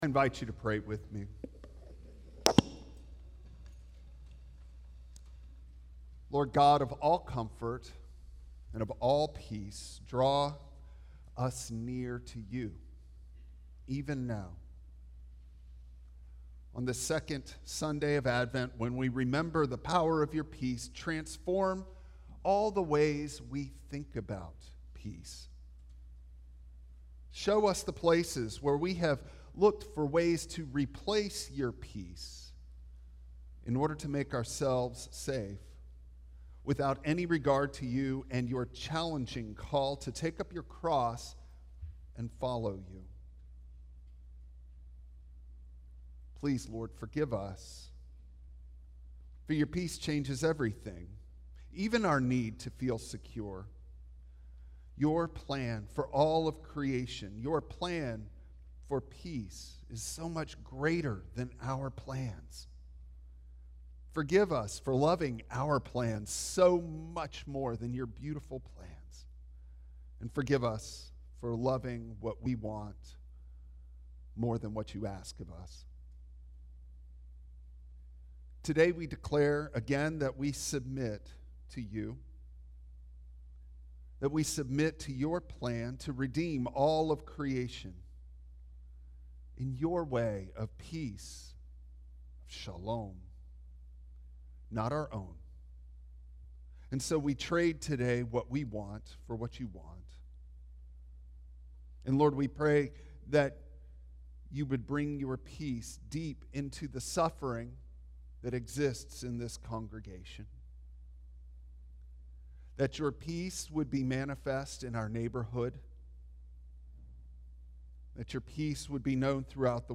Matthew 1:18-21 Service Type: Traditional Service Bible Text